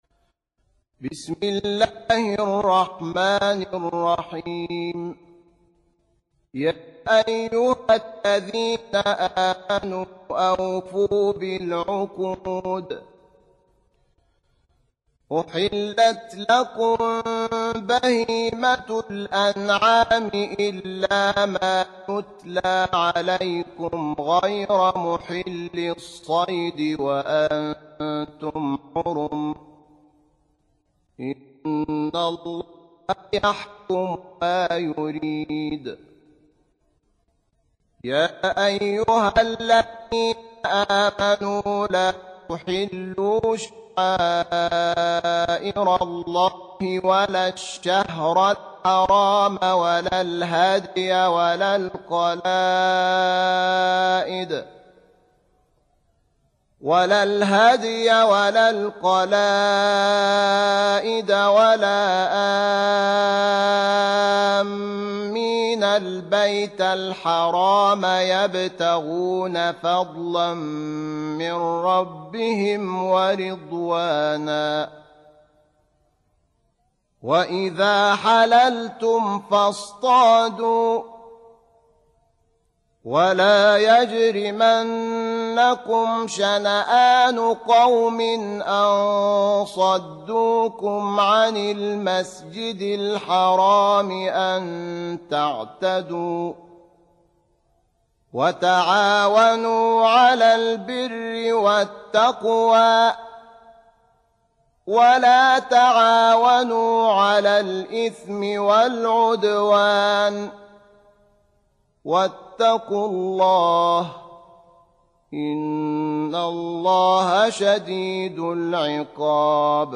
5. Surah Al-M�'idah سورة المائدة Audio Quran Tarteel Recitation Home Of Sheikh Shahat Muhammad Anwar
Surah Repeating تكرار السورة Download Surah حمّل السورة Reciting Murattalah Audio for 5.